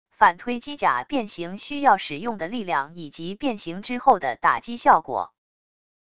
反推机甲变形需要使用的力量以及变形之后的打击效果wav音频